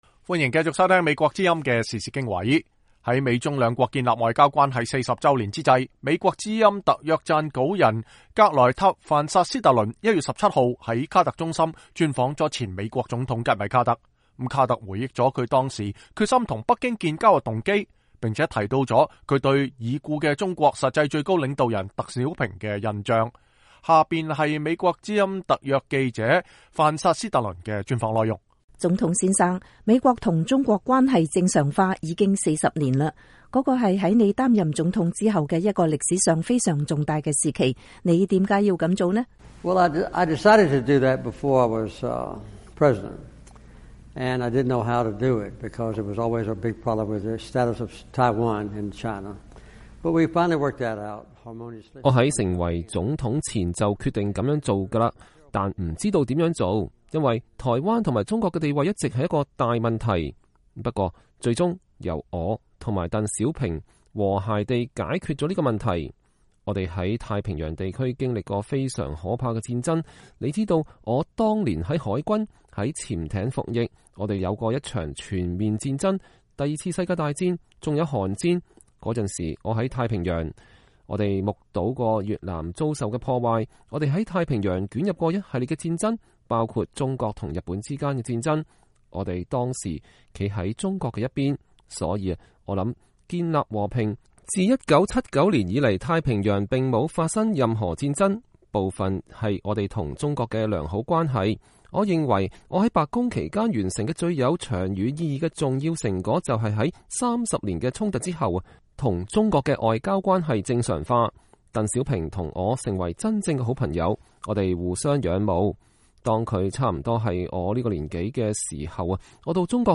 VOA專訪：前總統卡特回憶為何要與中國建交
在美中建交四十週年之際，美國之音特約撰稿人格萊塔·範·薩斯特倫 1月17日在卡特中心專訪了前總統吉米·卡特。卡特回憶了他當時決心與北京建交的動機並且談到了他對已故中國實際最高領導人鄧小平的印象。這位前總統和諾貝爾和平獎得主還談到了對當前美中關係及兩國關係未來走向的看法。